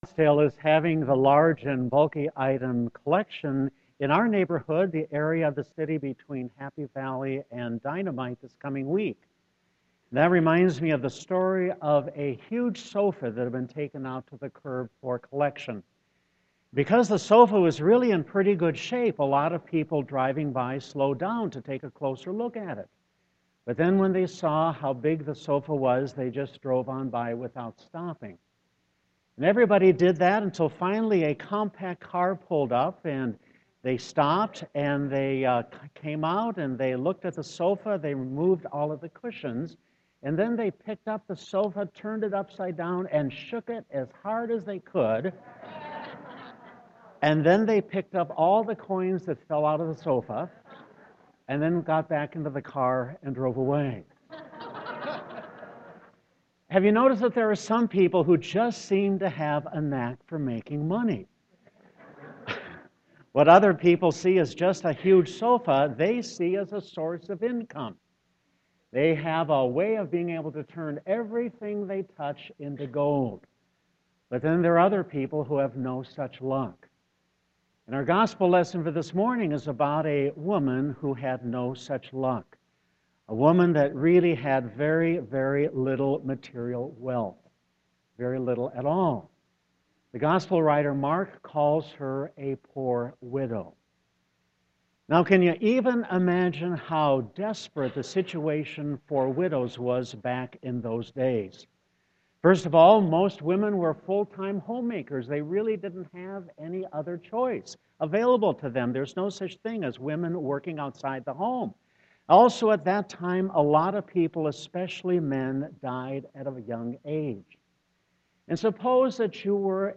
Sermon 11.8.2015